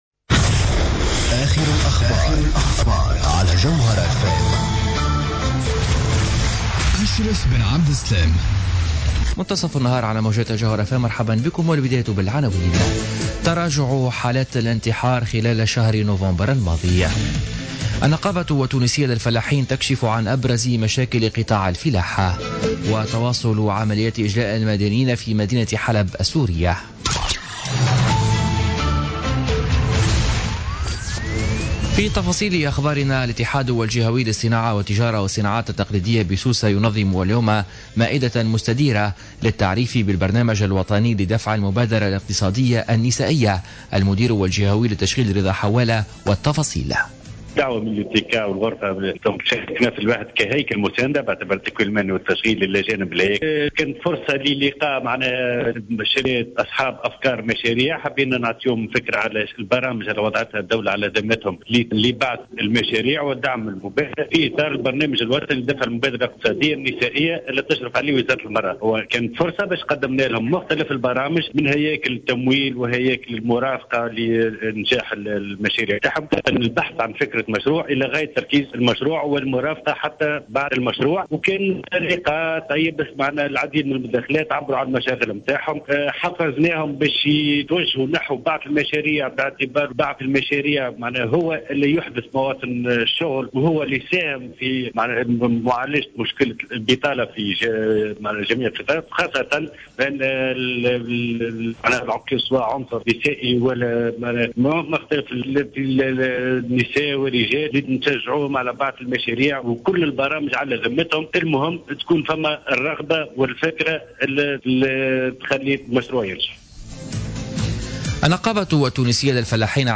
نشرة أخبار منتصف النهار ليوم الخميس 22 ديسمبر 2016